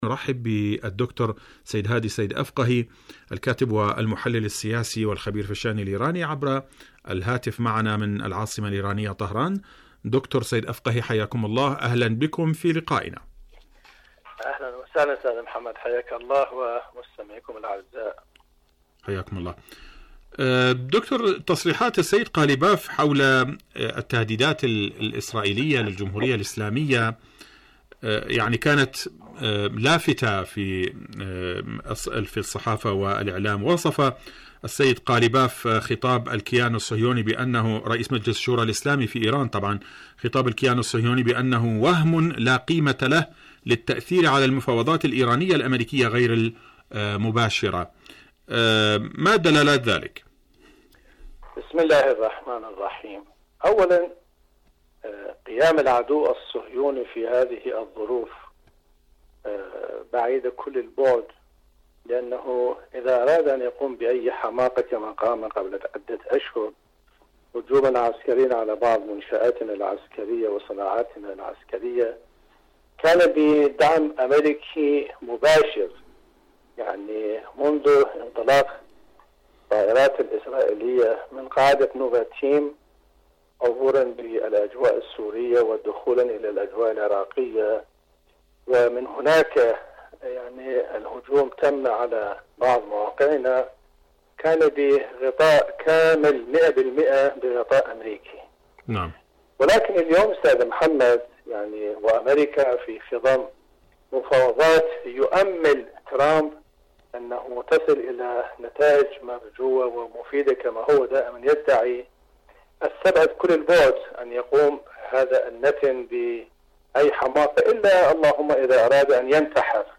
مقابلات برامج إذاعة طهران العربية برنامج حدث وحوار مقابلات إذاعية التهديد الإسرائيلي لإيران من ضروب الوهم والخيال شاركوا هذا الخبر مع أصدقائكم ذات صلة آليات إيران للتعامل مع الوكالة الدولية للطاقة الذرية..